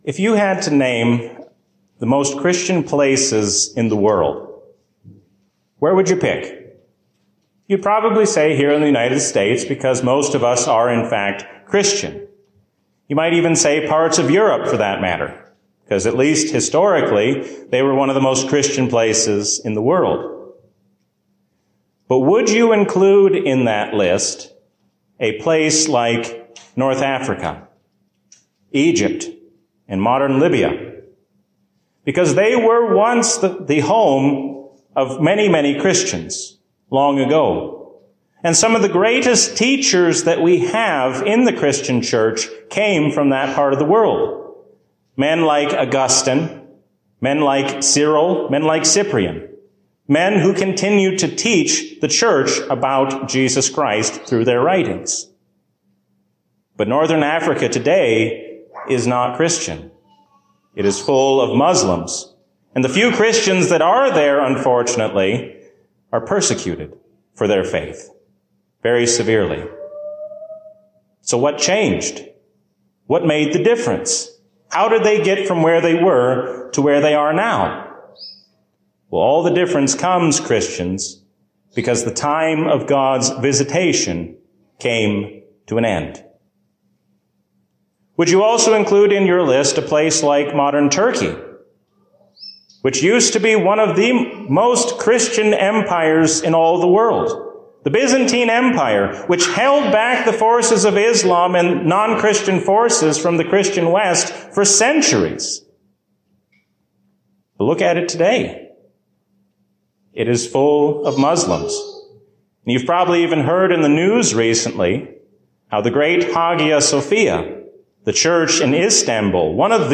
A sermon from the season "Trinity 2024." Doing what God says means following after Him without making excuses.